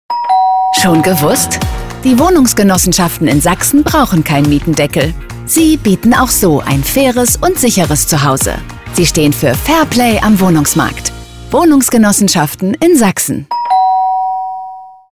Spot "Mietendeckel"